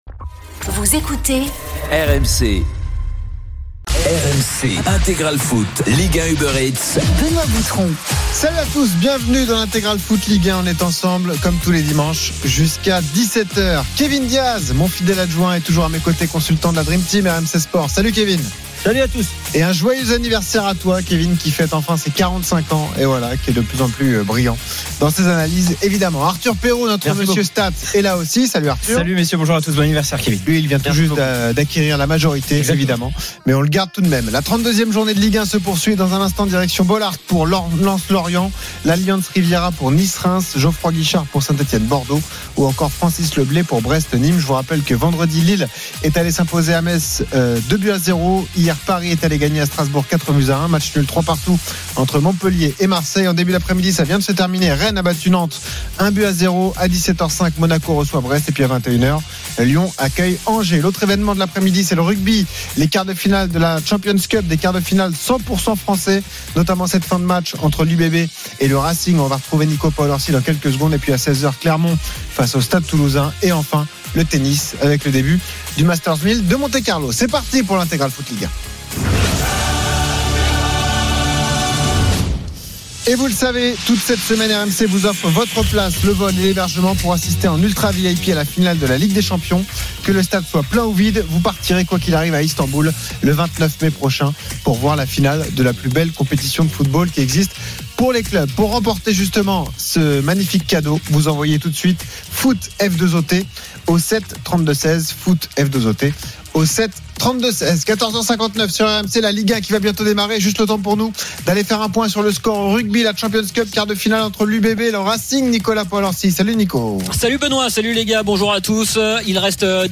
Tous les matchs en intégralité, sur RMC la radio du Sport.